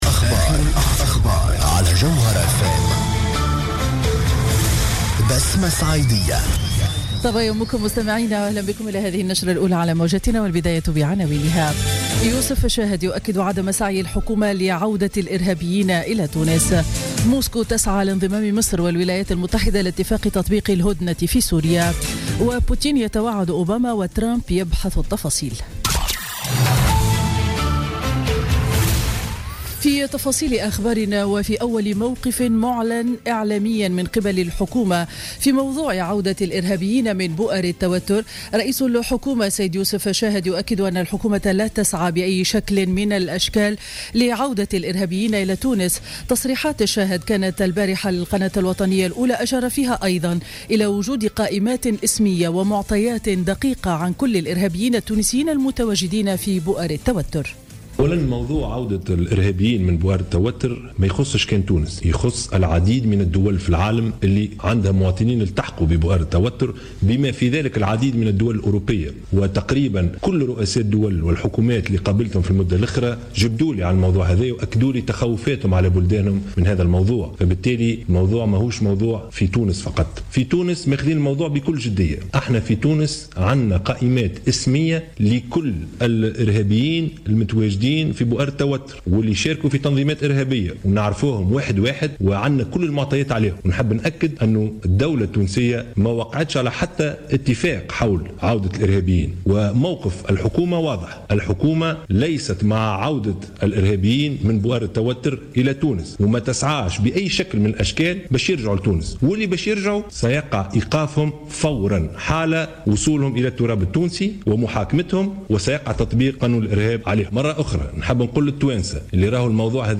نشرة أخبار السابعة صباحا ليوم الجمعة 30 ديسمبر 2016